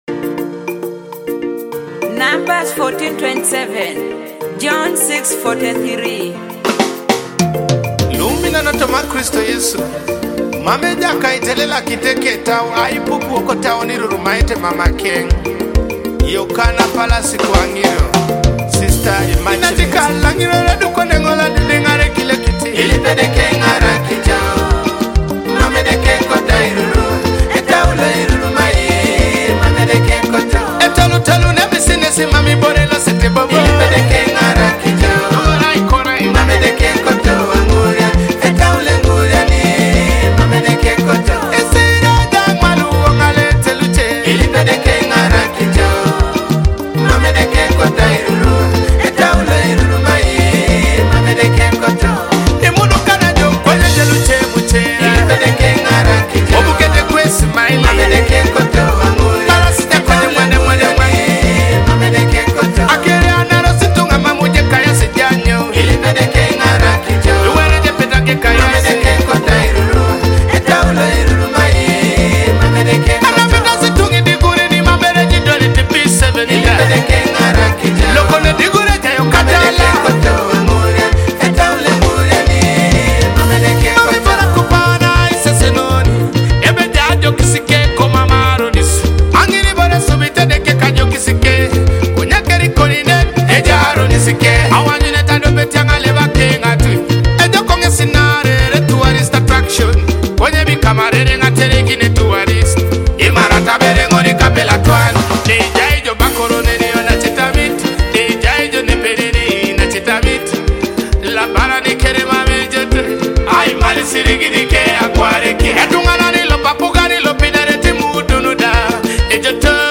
a heartfelt Teso song